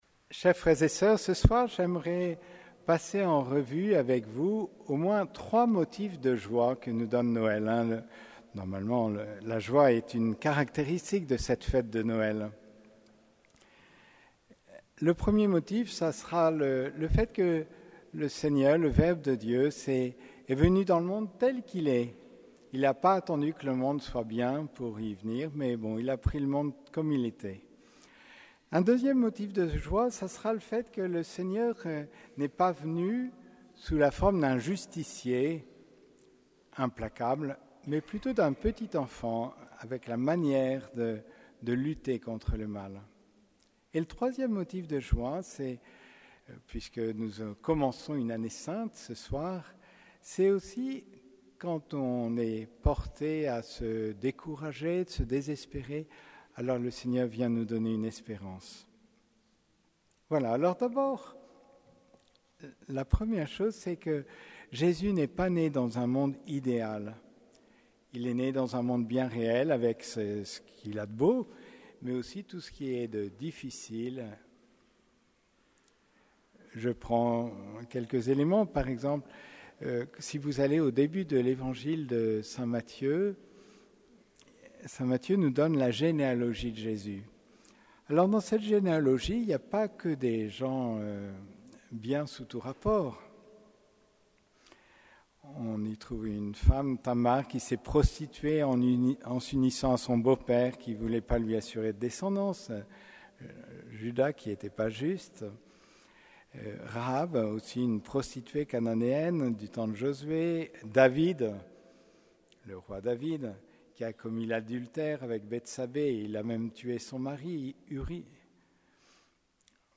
Homélie de la messe de la Nativité du Seigneur (messe de la nuit)